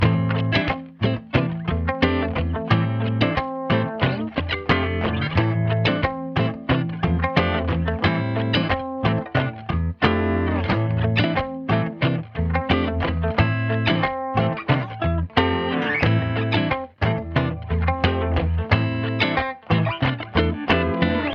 什么是设备 90bpm Am
描述：嘻哈吉他在Am (C) F6中的应用
Tag: 90 bpm Hip Hop Loops Guitar Electric Loops 3.59 MB wav Key : A